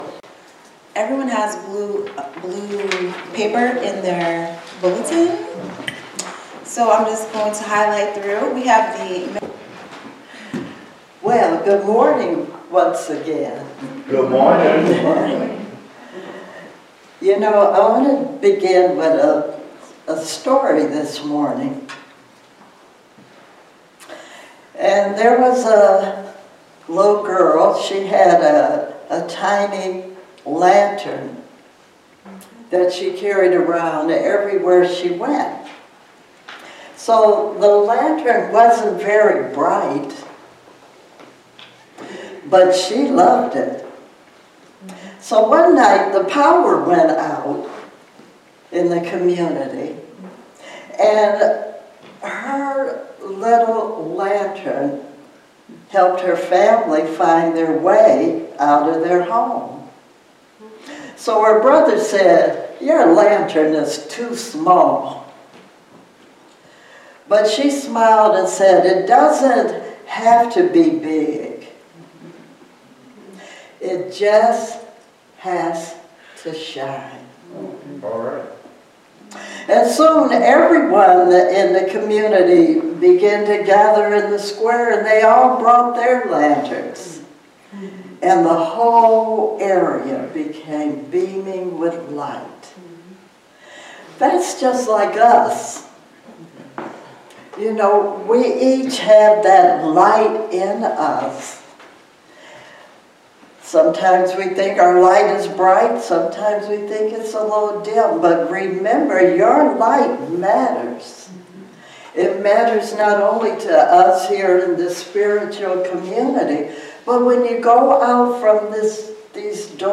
Series: Sermons 2026